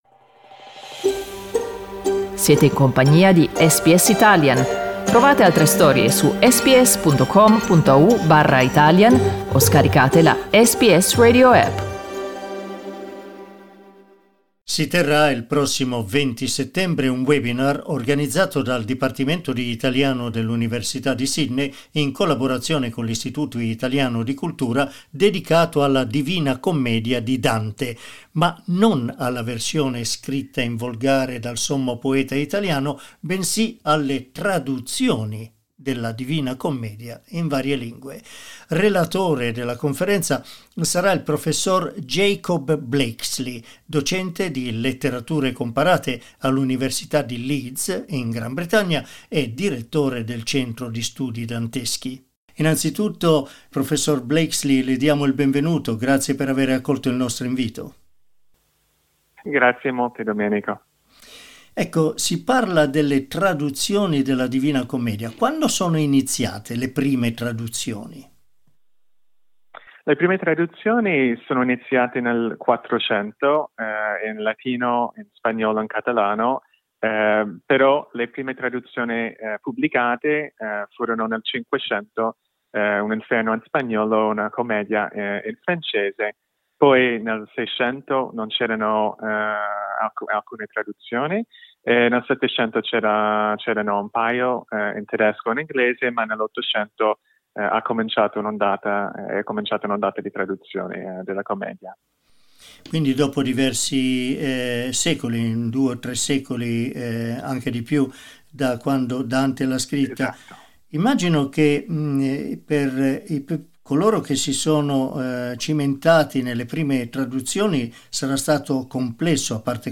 Ascolta l'intervista: LISTEN TO La storia delle traduzioni della Divina Commedia SBS Italian 11:21 Italian Le persone in Australia devono stare ad almeno 1,5 metri di distanza dagli altri.